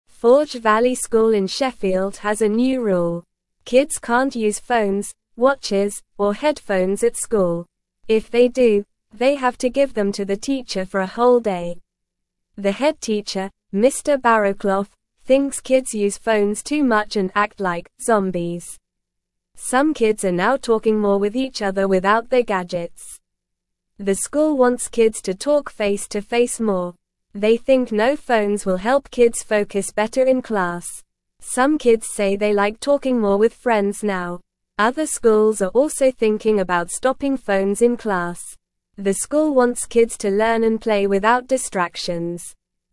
Normal
English-Newsroom-Beginner-NORMAL-Reading-No-Phones-Allowed-at-Forge-Valley-School-in-Sheffield.mp3